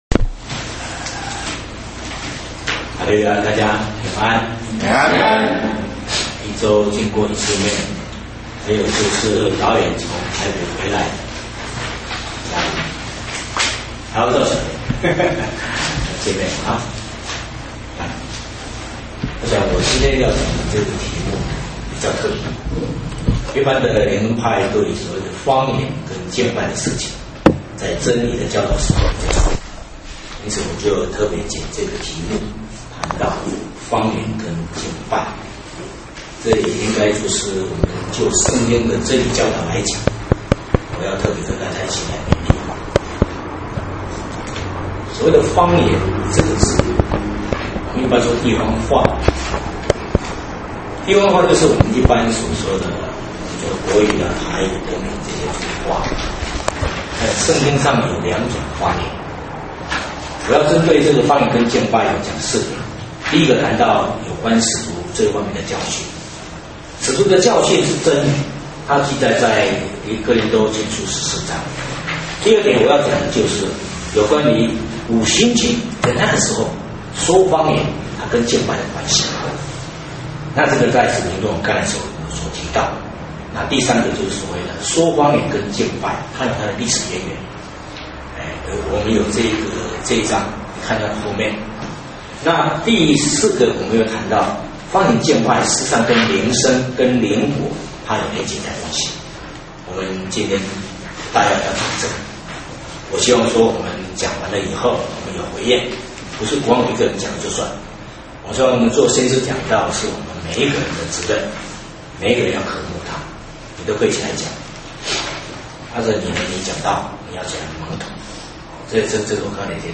地點：嘉義會堂。